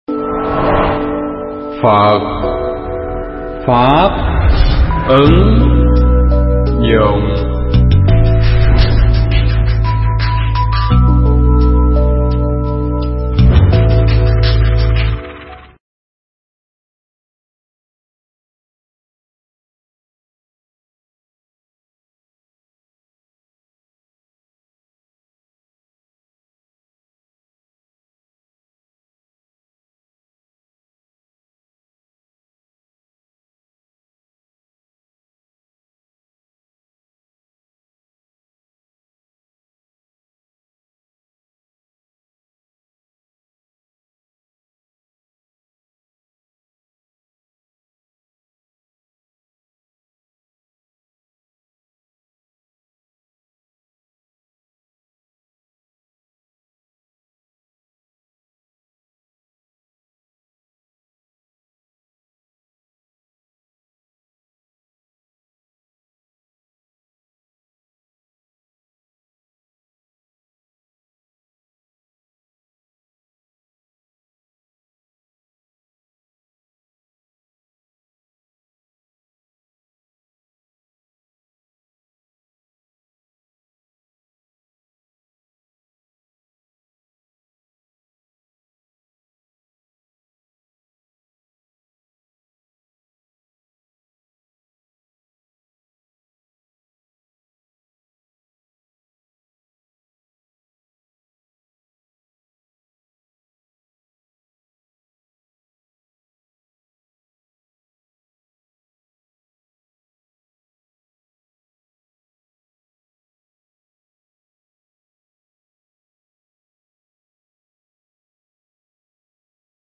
Pháp âm Giàu Và Nghèo
Pháp thoại
tại chùa Đức Linh - khóm Mỹ Thành, phường Vĩnh Mỹ, Châu Đốc, An Giang